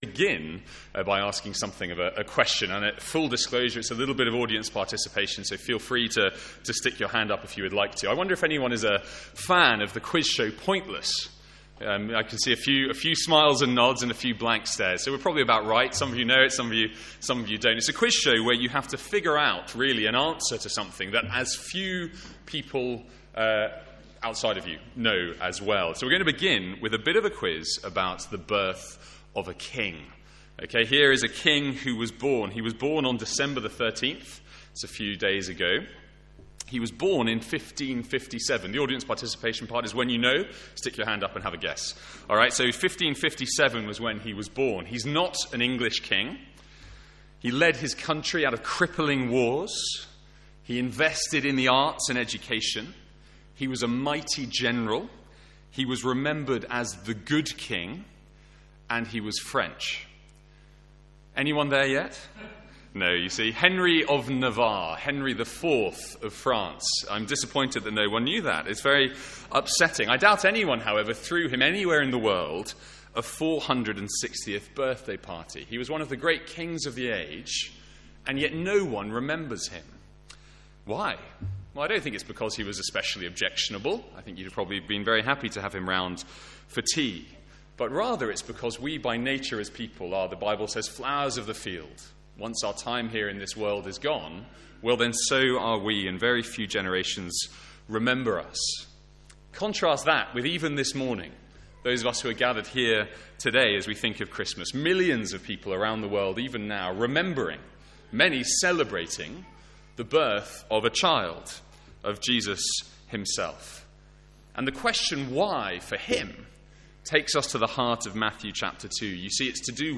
From our morning carol service.